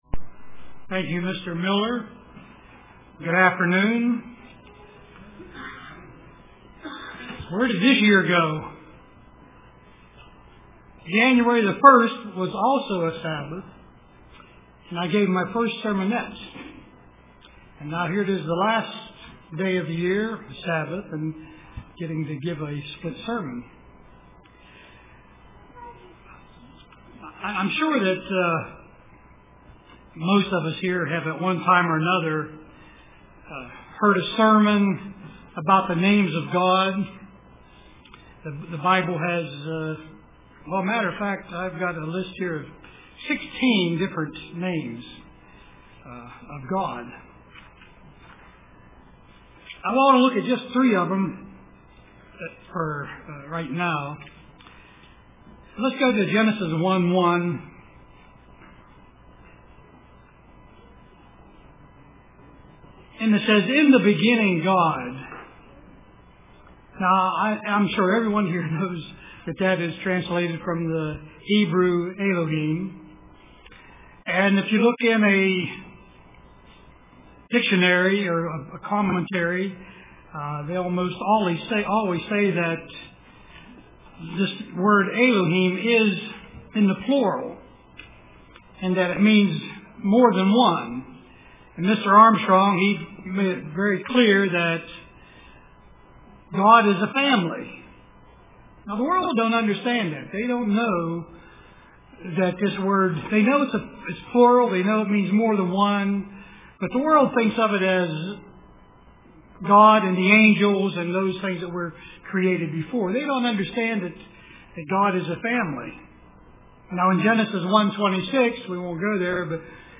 Print Bible Names for the Church UCG Sermon Studying the bible?